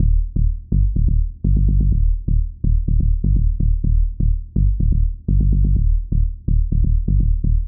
• Tech House Bass Rhythm 2 Punchy G.wav
Loudest frequency 70 Hz
Tech_House_Bass_Rhythm_2_Punchy_G_0JA.wav